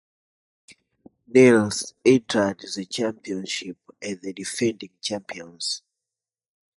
Phát âm là (IPA)
/dəˈfɛndɪŋ/